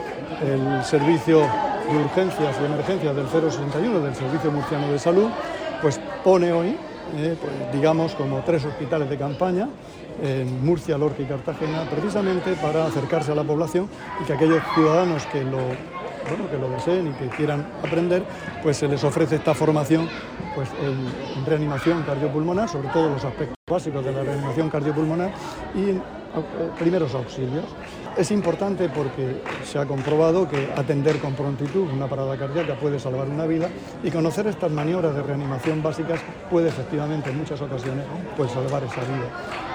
Declaraciones del consejero de Salud, Juan José Pedreño, sobre la importancia de conocer las maniobras de reanimación cardiopulmonar.